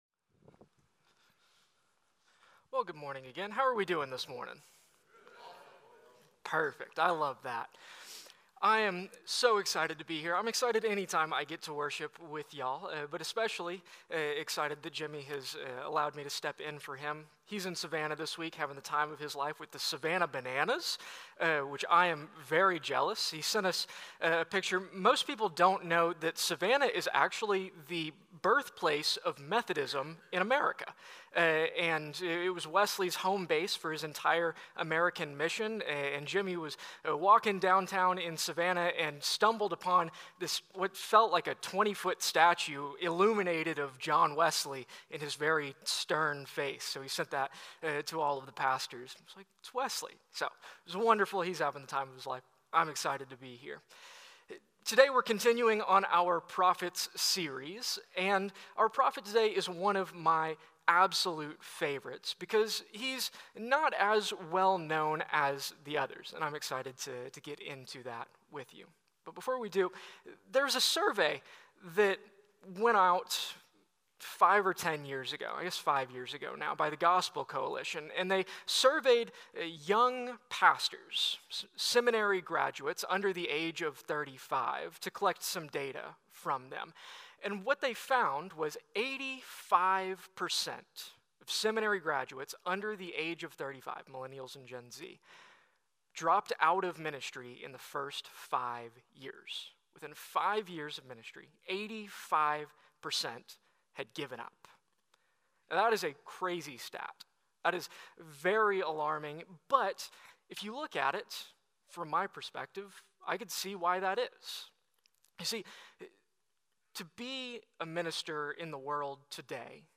A message from the series "Prophets."